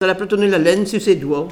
Saint-Hilaire-des-Loges
Catégorie Locution